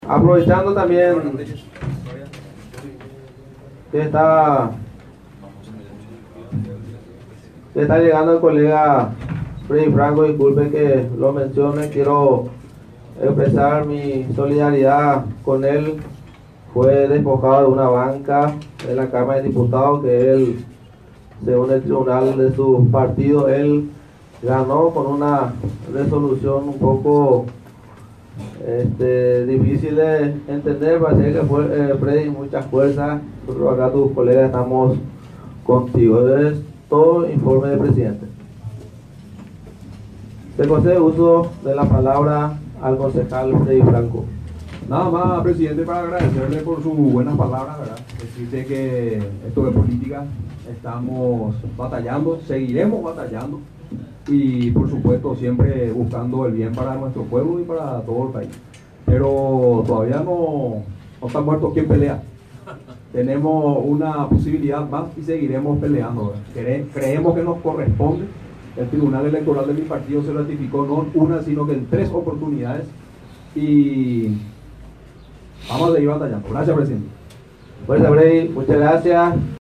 AUDIO DE LA SOLIDARIDAD Y LA RESPUESTA DE FRANCO
Sesión-Ordinaria-de-la-Junta-Municipal...-Junta-Municipal-de-San-Lorenzo-1.mp3